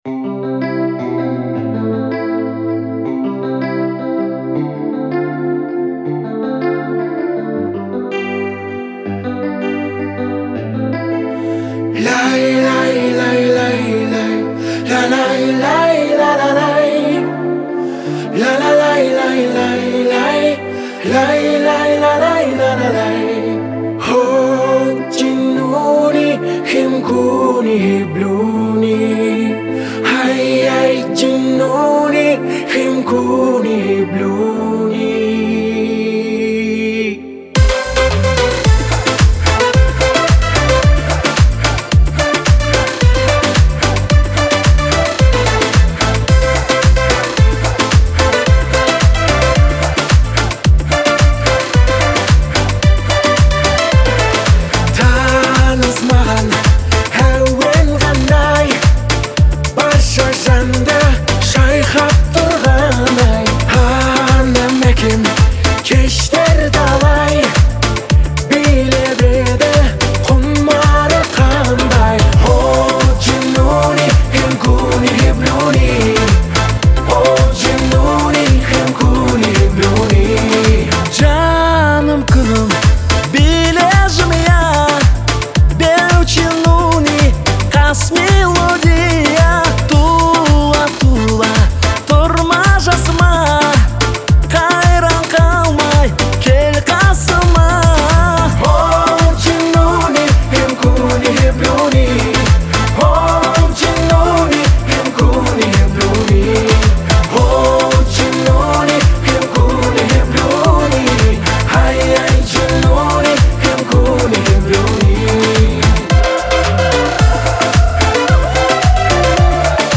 от нашей казахстанской группы